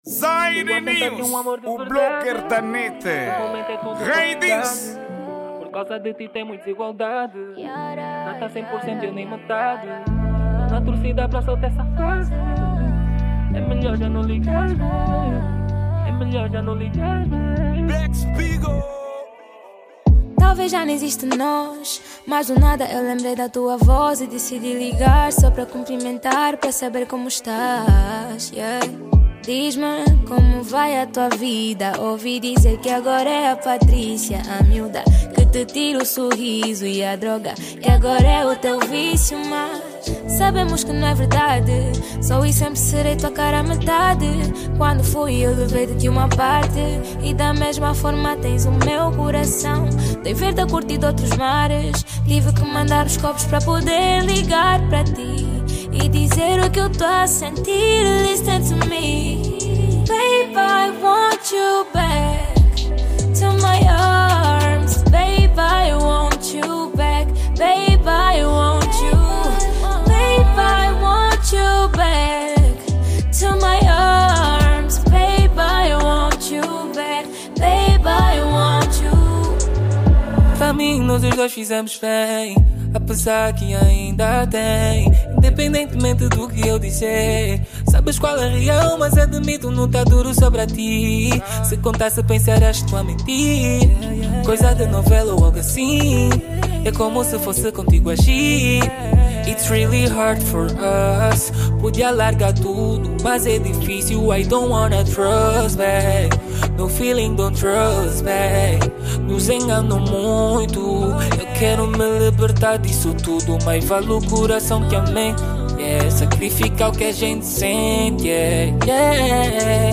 Gênero: RnB